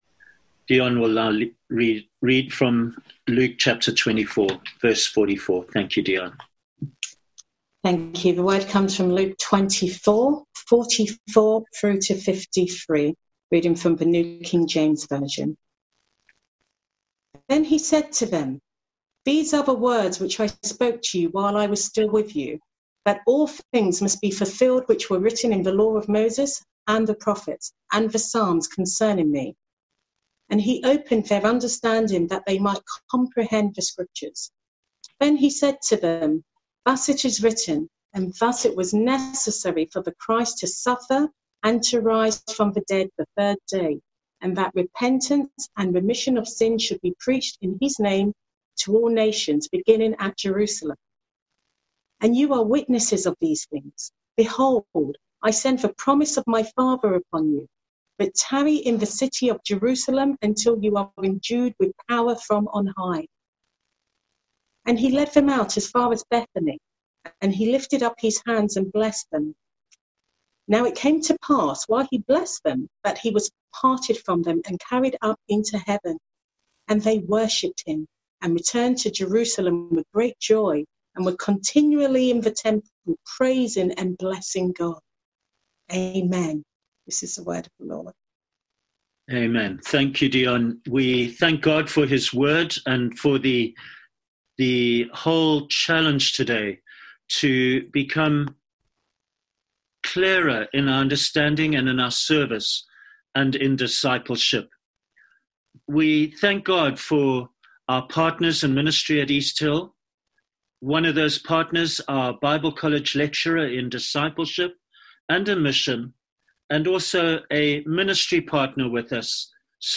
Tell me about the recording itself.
Coronavirus the decision was taken to live-stream services online.